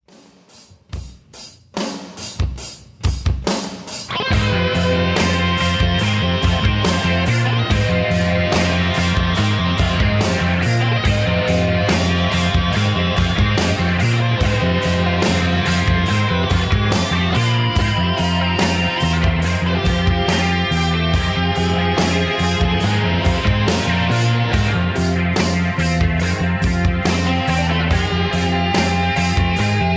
Tags: rock